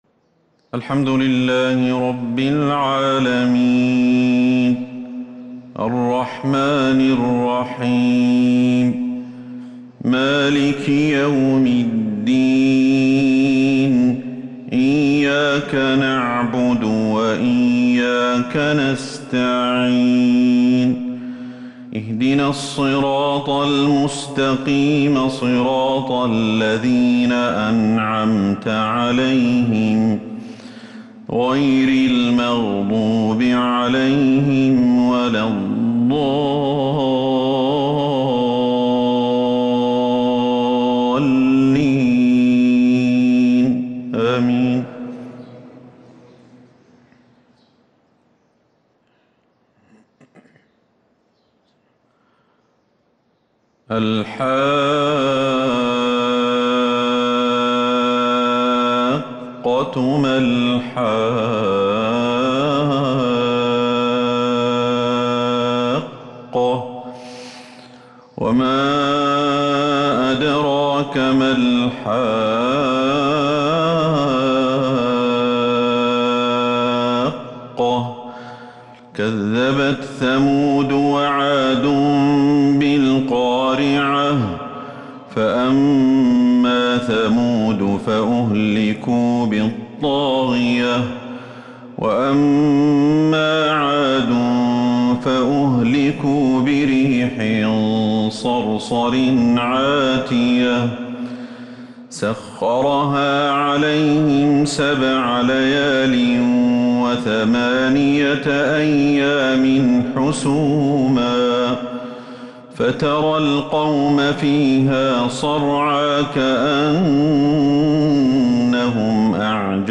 فجر الأحد 7 محرم 1443 هـ ما تيسر من سورة {الحاقة} > 1443 هـ > الفروض